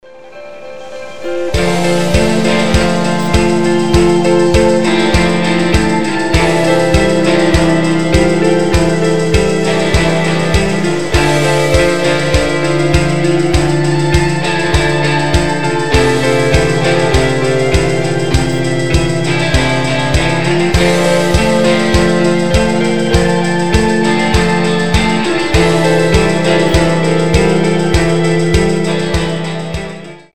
• Качество: 320, Stereo
без слов
красивая мелодия
инструментальные
Ambient
мечтательные
post-rock
пост-рок
Волнующе прекрасная мелодия